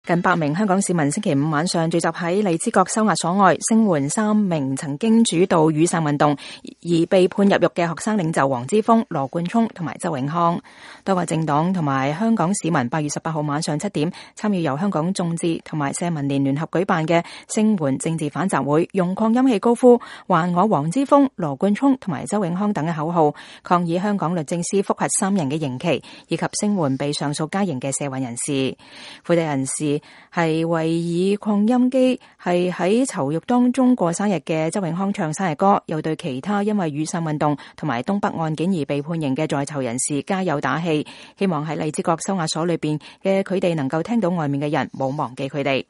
近百名香港市民星期五晚上聚集在荔枝角收押所外，聲援三名曾經主導雨傘運動而被判入獄的學生領袖黃之鋒、羅冠聰和周永康。集會人士為以擴音器為在囚獄中過生日的周永康唱生日歌，又對其他因雨傘運動和東北案件而判刑的在囚人士加油打氣，希望在荔枝角收押所裡的他們能聽到外面的人沒有忘記他們。